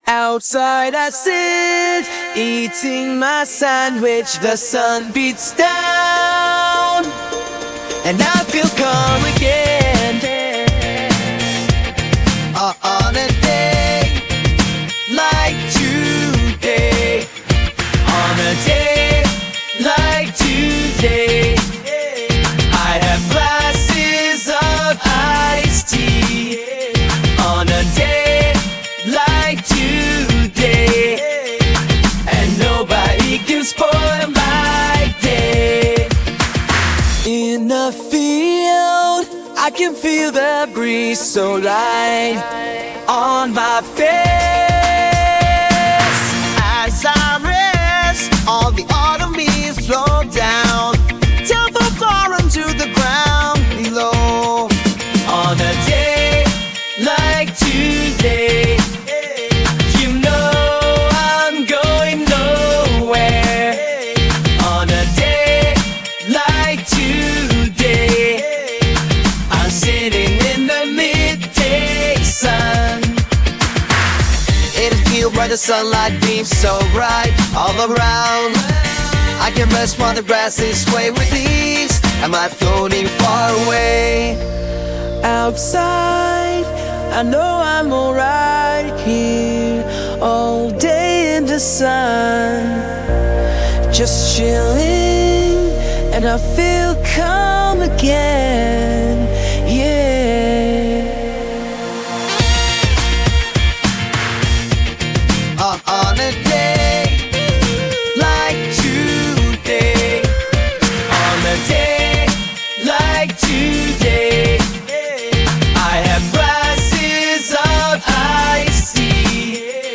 Sung by Udio